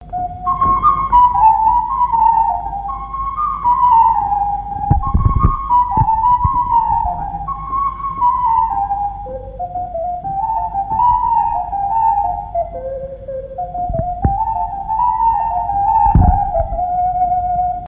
Mémoires et Patrimoines vivants - RaddO est une base de données d'archives iconographiques et sonores.
danse : branle : avant-deux
Assises du Folklore
Pièce musicale inédite